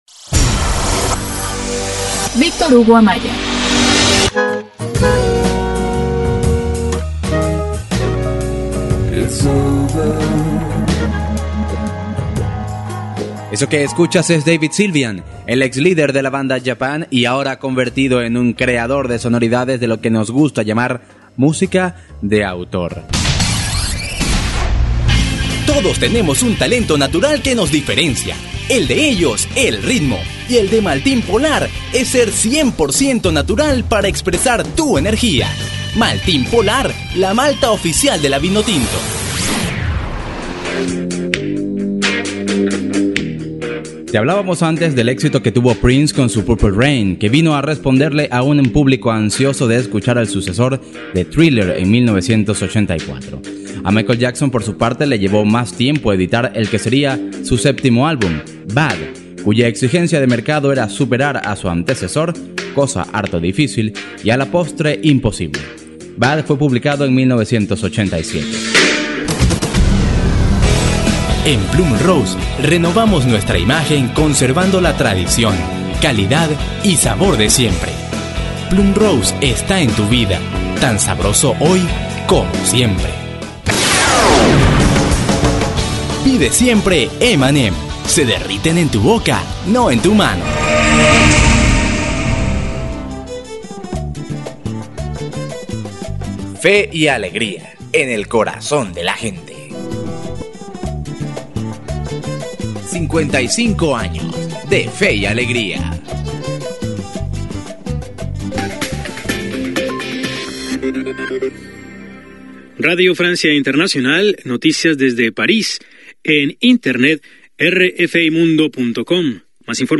Locutor con acento neutro, voz suave, potente, divertida y animada
spanisch Südamerika
Sprechprobe: Werbung (Muttersprache):
Young male voice for voice over work. Relax and fun for commercials, professional for presentations and narration.